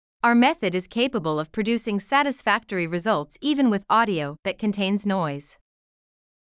noisy_audio_narrative.wav